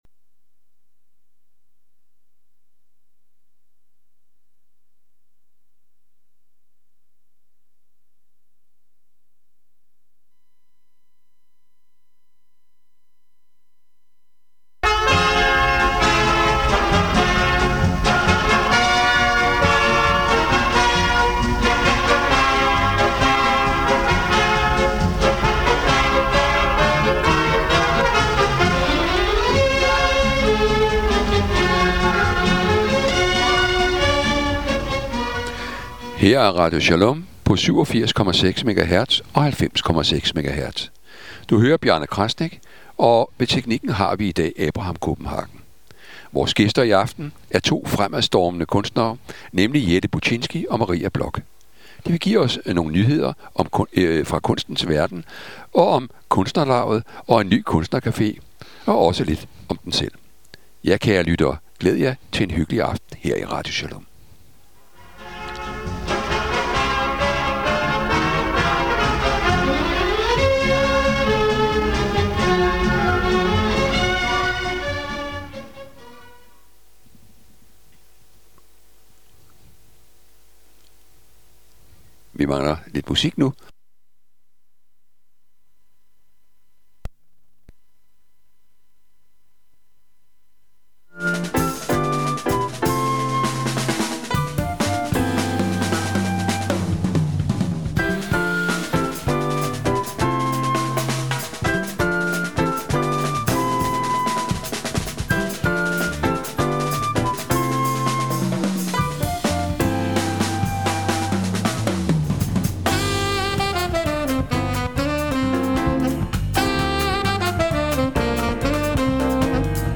Interview med og om kunstnere